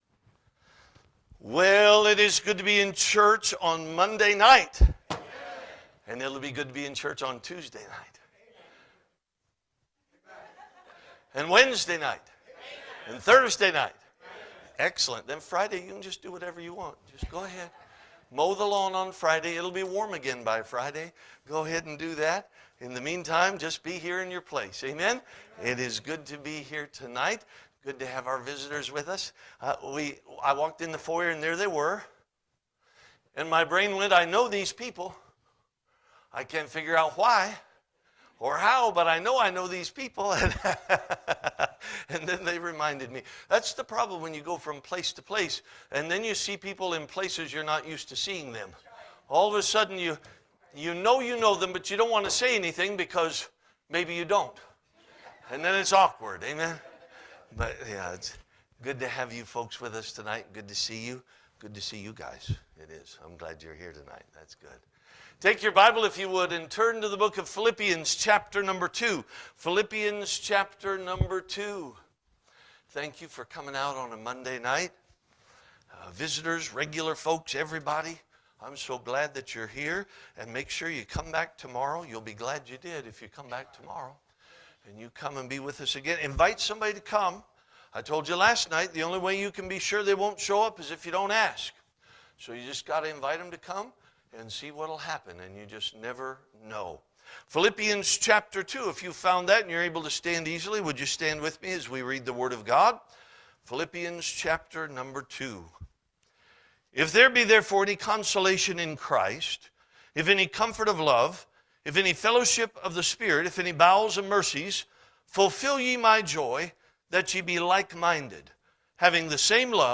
Spring Revival 2026 Passage: Philippians 2 Service Type: Revival « Spring Revival 2026